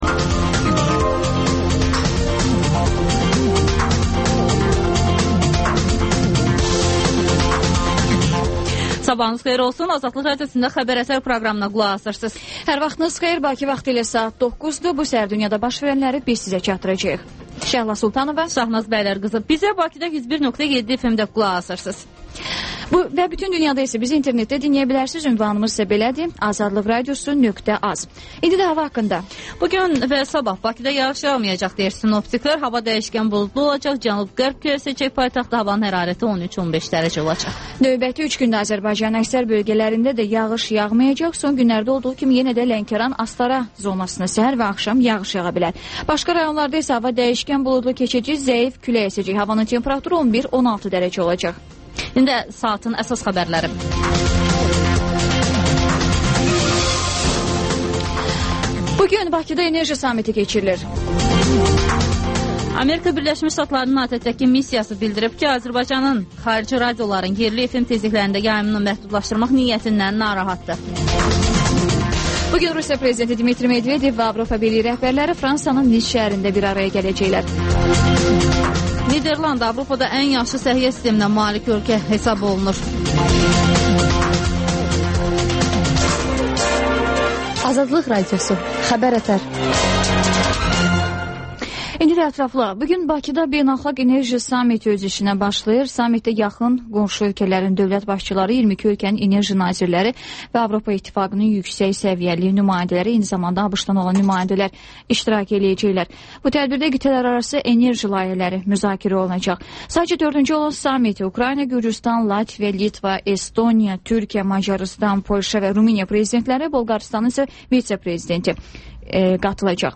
Xəbər-ətər: xəbərlər, müsahibələr və 14-24: Gənclər üçün xüsusi veriliş